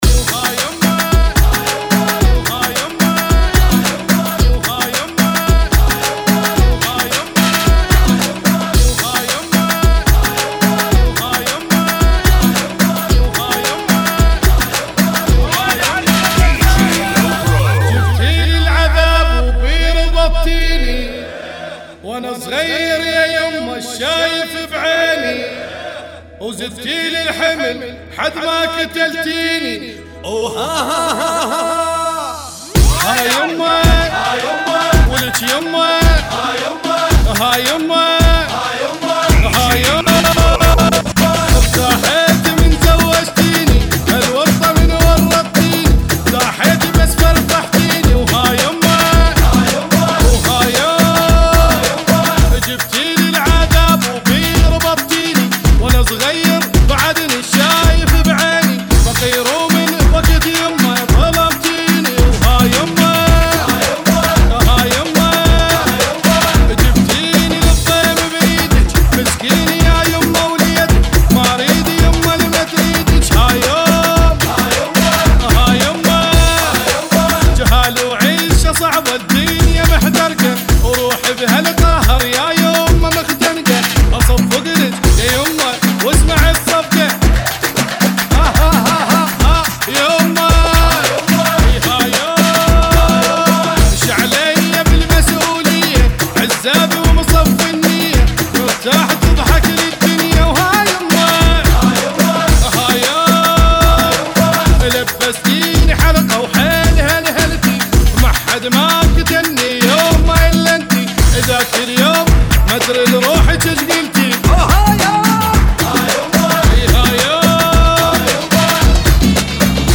[ 110 bpm ] FunKy